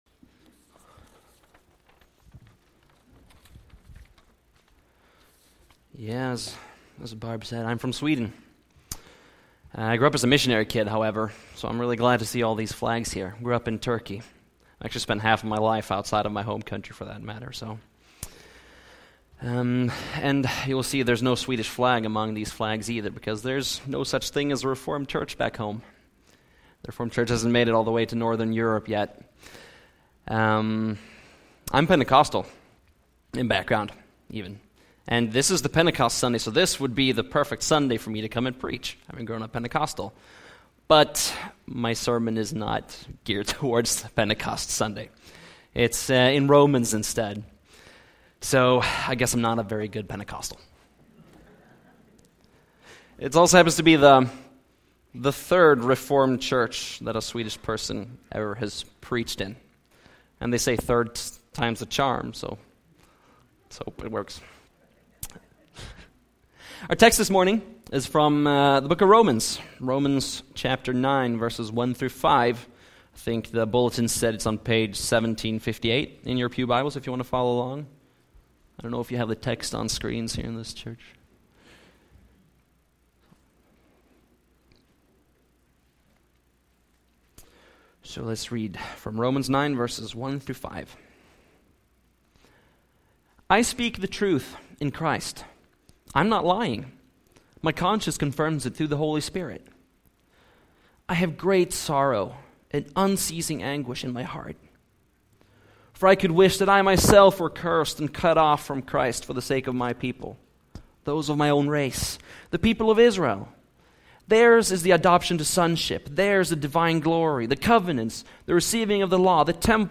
Romans 9:1-5 Service Type: Sunday AM Bible Text